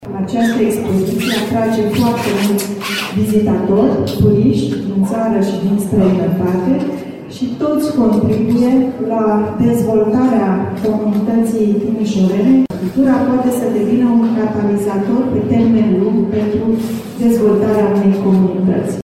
Deschiderea oficială a avut loc în prezența premierului Marcel Ciolacu și a ministrului Culturii, Raluca Turcan.
Cultura poate fi un catalizator pentru dezvoltarea unei comunități, a mai spus ministrul Culturii.